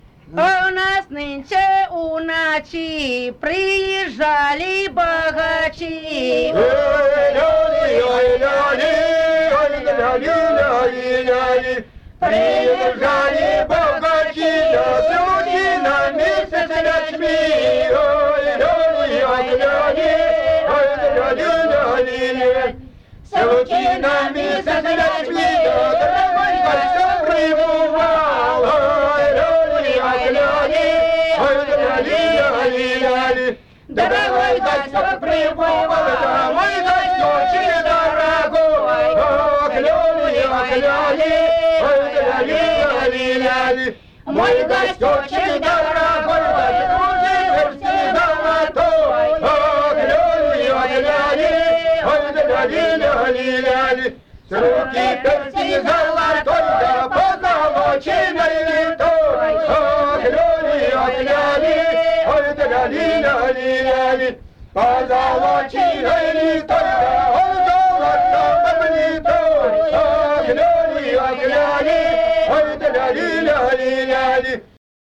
Большинство свадебных песен курской традиции исполняется под пляску, они звучат в быстром темпе, имеют четкий ритм, отражающий ритм плясового шага, сопровождаются хлопками в ладони, выкриками-ихами. По мнению певцов, они поют в один голос, но мелодия и ритм у каждого исполнителя варьируется, результатом чего является плотная многоголосная фактура с ритмической полифонией пропевания слогов, особенно в припевах-лёлях.
Свадебная песня
из с. Белица Беловского р-на Курской обл.